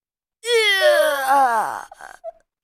Death/Dying Female Fighter/Warrior Voice Over | Vocal sound for character
1_dying.ogg